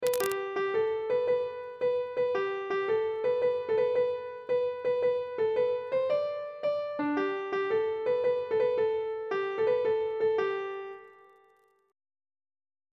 Air.